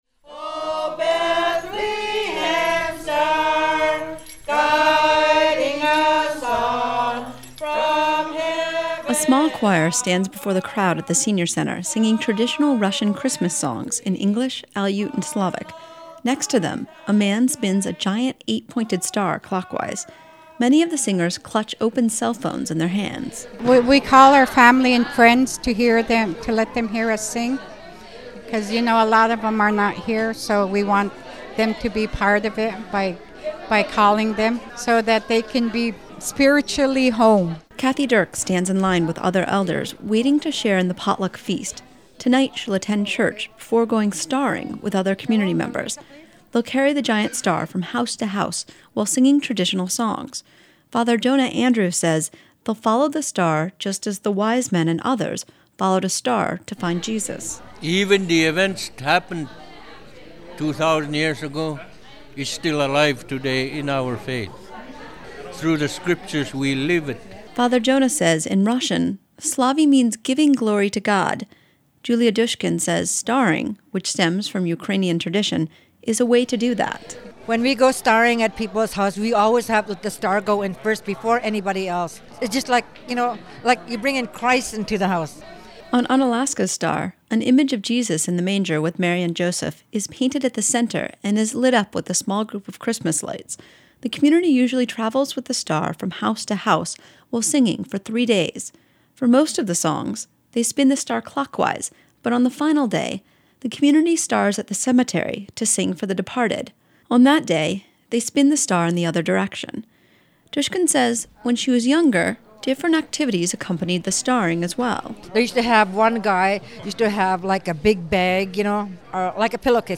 Community members from Unalaska gathered at the Senior Center today to sing carols and share traditions.
unalaskans-celebrate-russian-christmas.mp3